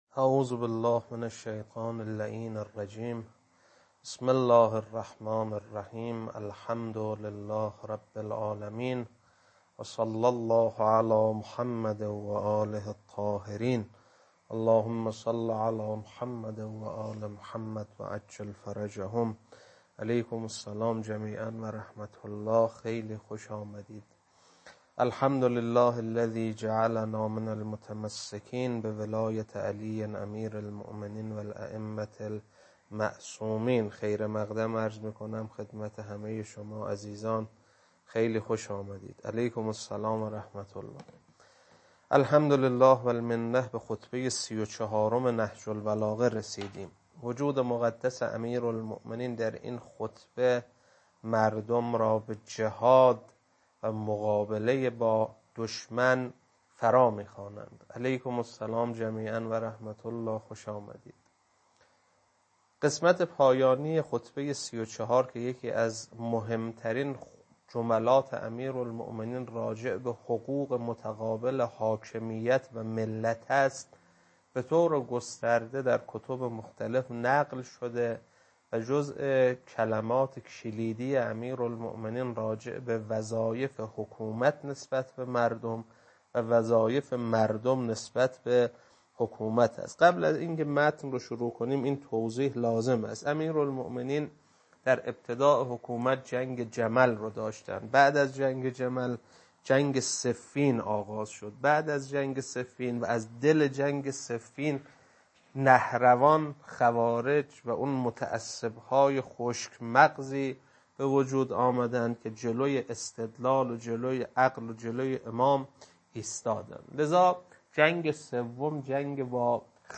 خطبه 34.mp3